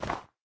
snow4.ogg